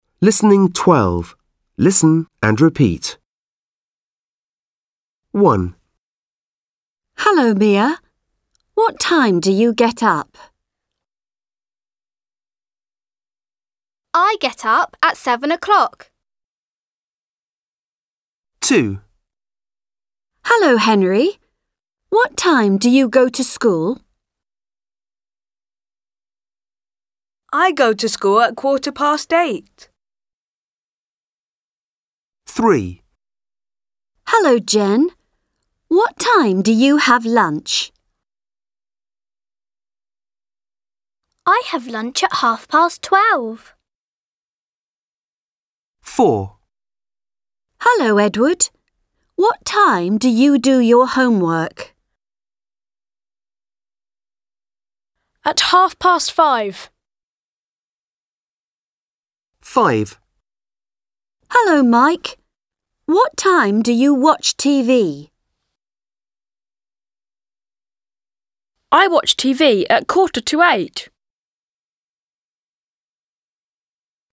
Kliknij w play▶, słuchaj jak dzieci odpowiadają na pytania i powtarzaj.